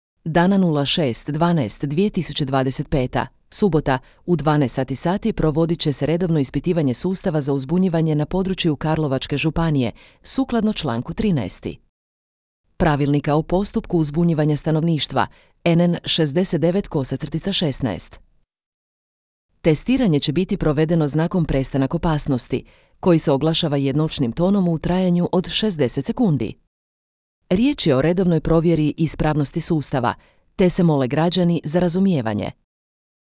Ispitivanje sustava za uzbunjivanje u Karlovačkoj županiji
Testiranje će biti provedeno znakom prestanak opasnosti, koji se oglašava jednolčnim tonom u trajanju od 60 sekundi.